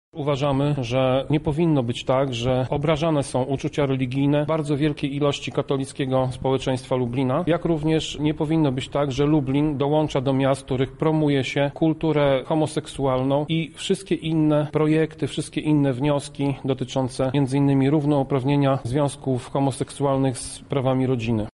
– Uważam, że jest to prowokacja– mówi Tomasz Pitucha, przewodniczący klubu radnych PiS: